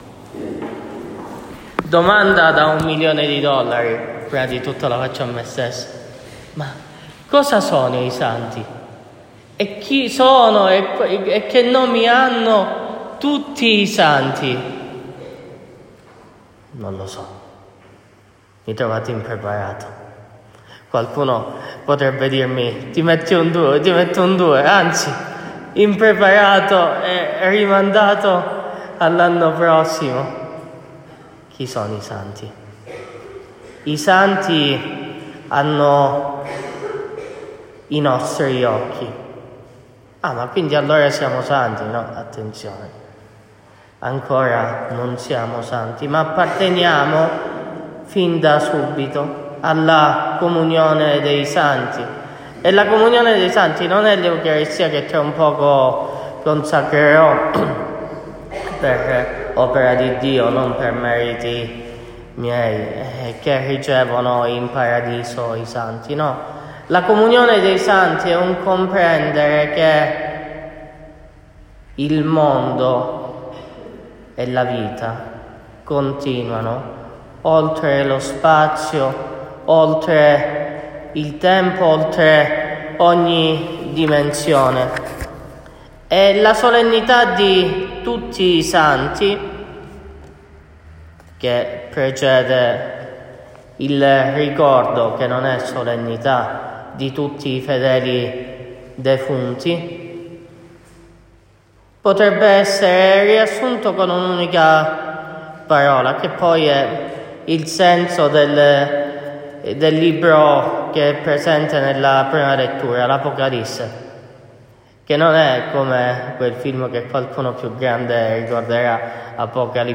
Omelia della Solennità di tutti i santi